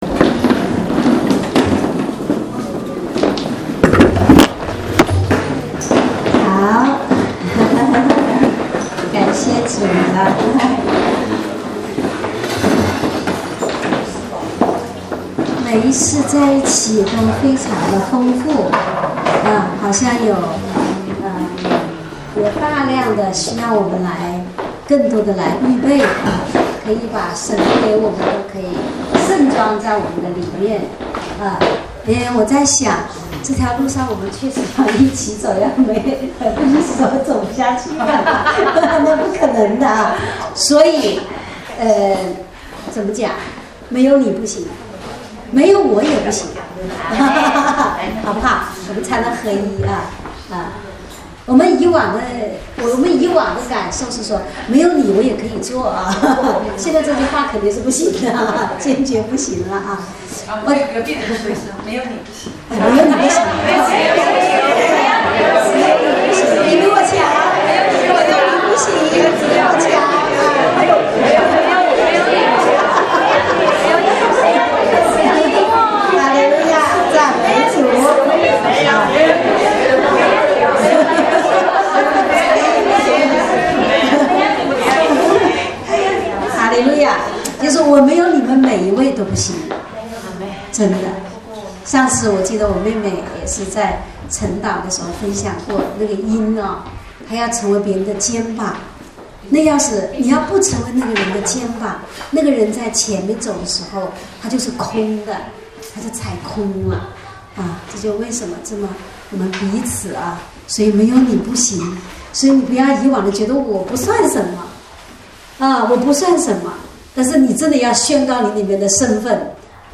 正在播放：--主日恩膏聚会（2015-09-20）